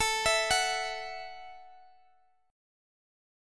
A 5th 6th